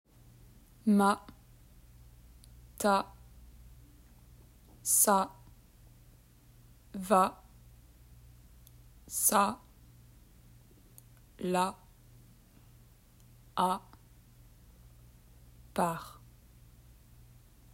Dialogue
Pay attention to the sound [a], which is spelled a in French.